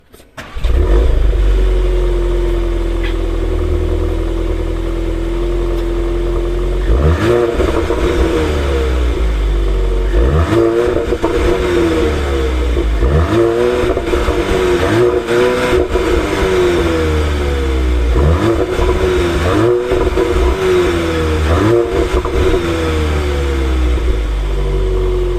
Écoutez le son du moteur !
AudiTTS.mp3